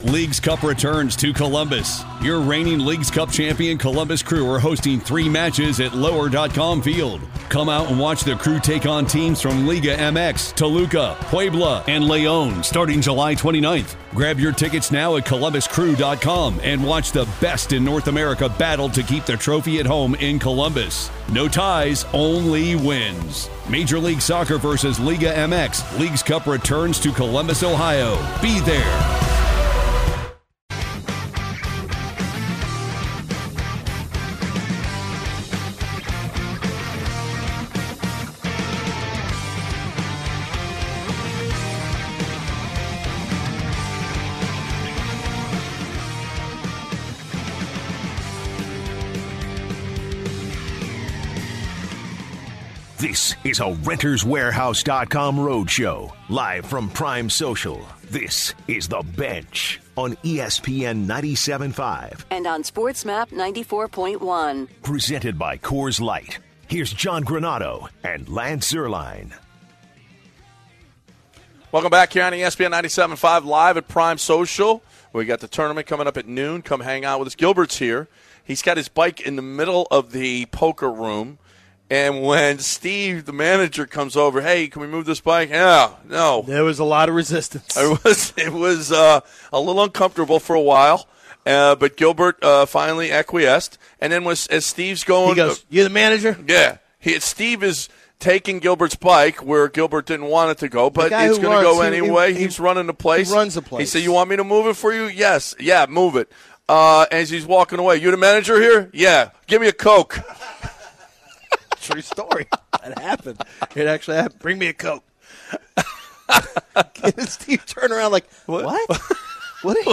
They take in calls about what “skinny-fat” means.